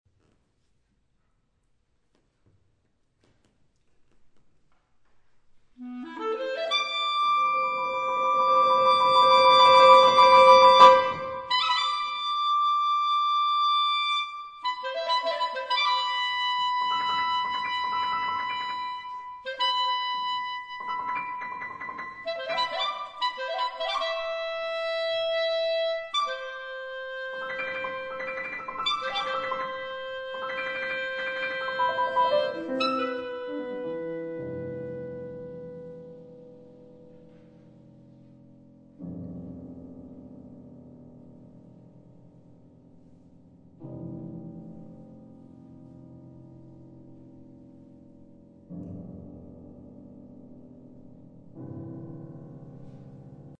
for Flute, Clarinet, and Piano